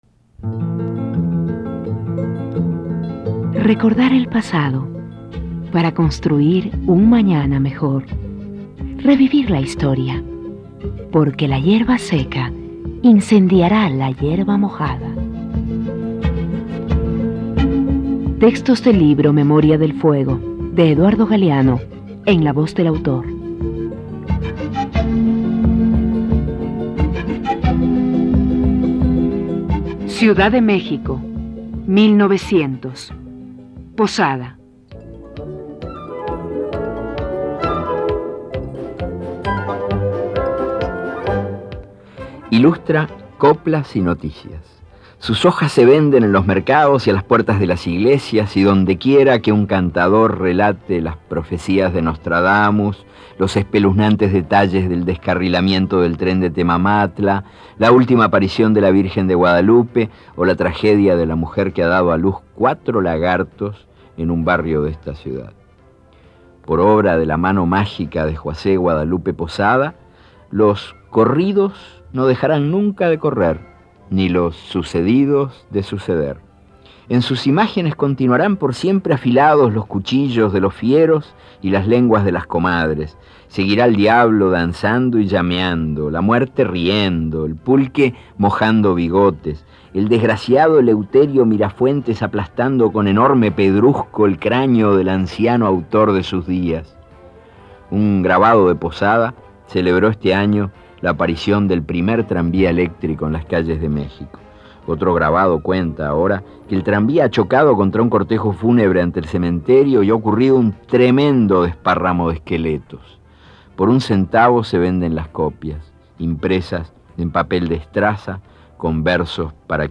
Este archivo de sonido ofrece la lectura del texto en la voz de su autor.